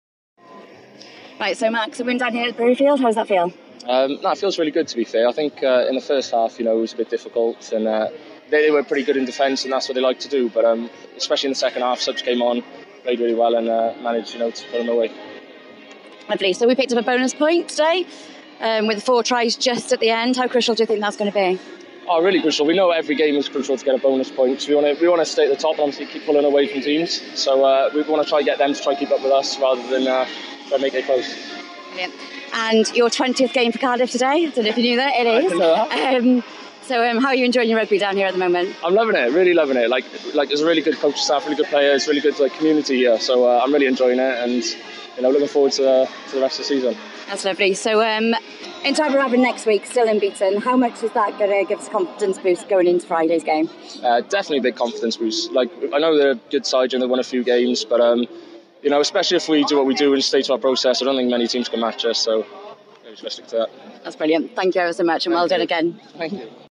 Post Match Interviews.